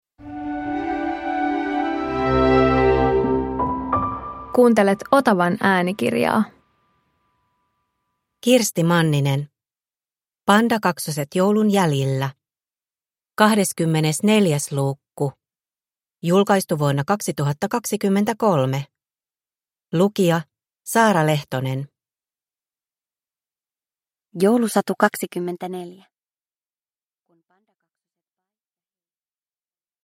Pandakaksoset joulun jäljillä 24 – Ljudbok